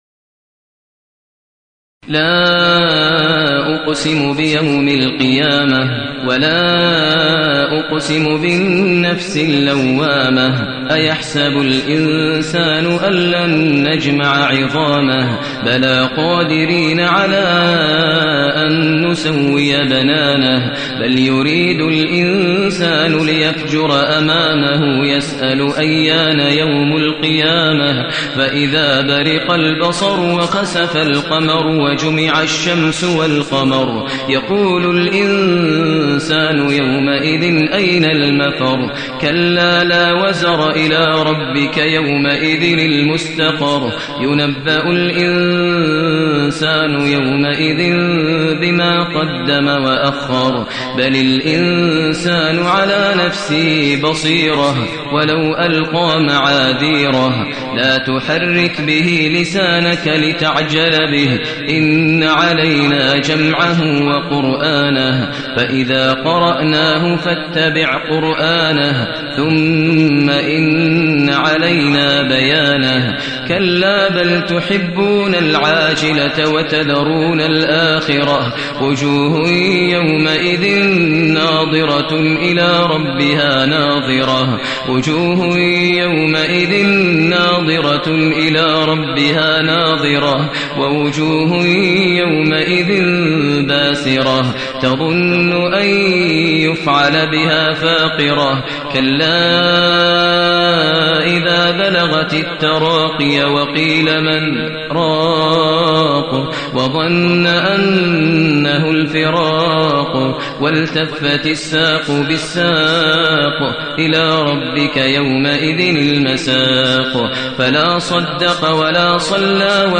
المكان: المسجد الحرام الشيخ: فضيلة الشيخ ماهر المعيقلي فضيلة الشيخ ماهر المعيقلي القيامة